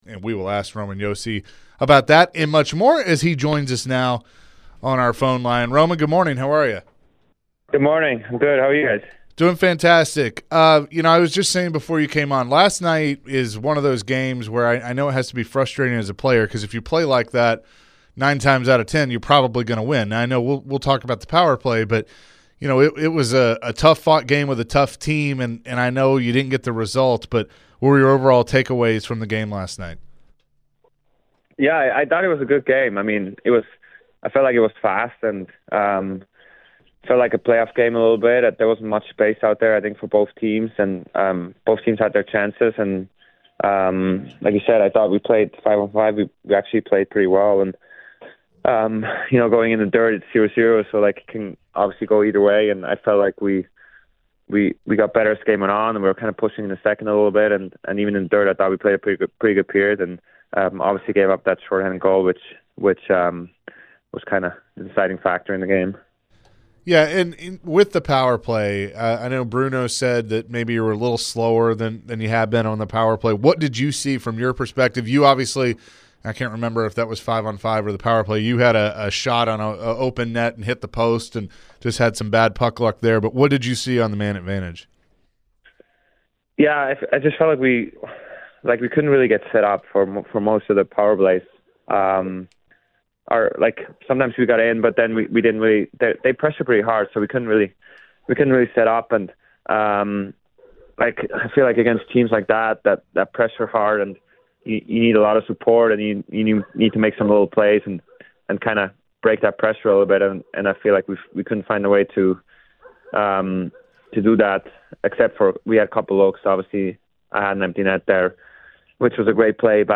Nashville Predators captain Roman Josi joined the show after last night's 3-0 loss to the Boston Bruins. Josi spoke on the powerplay unit and how it wasn’t their best performance of the season.